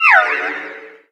Cri de Sucroquin dans Pokémon X et Y.